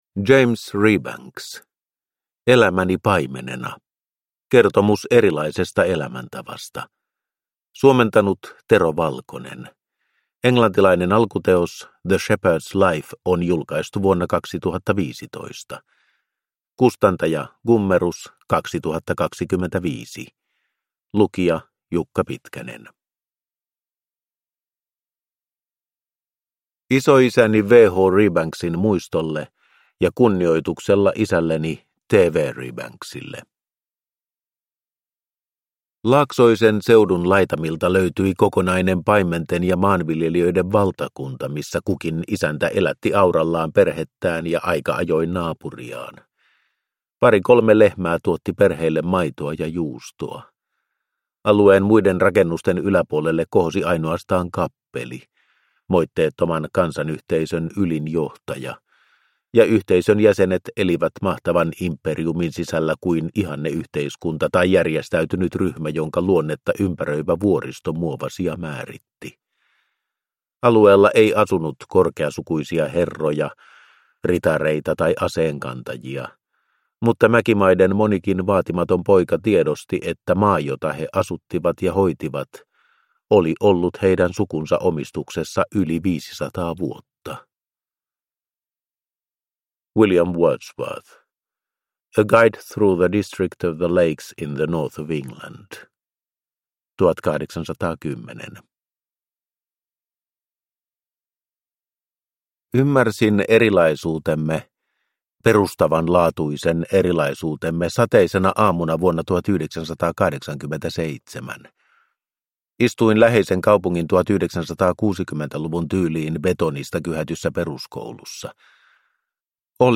Elämäni paimenena – Ljudbok